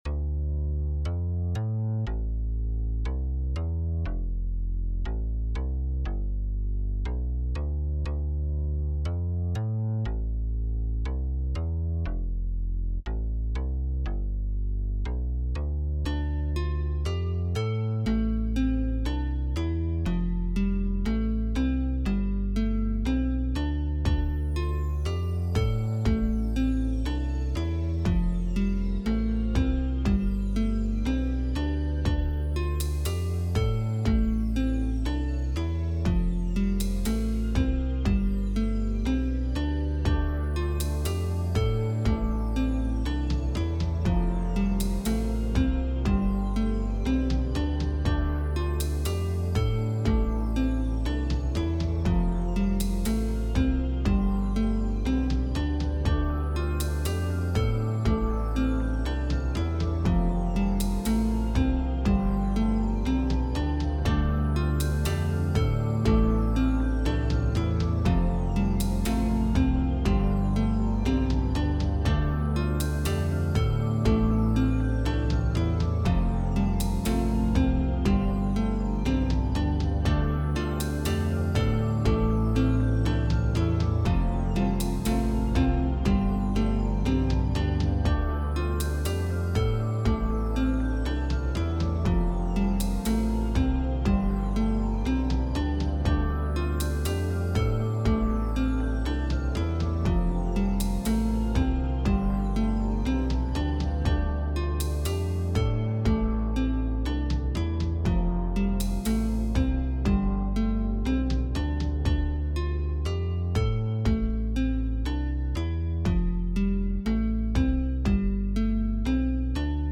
calm
peaceful
smooth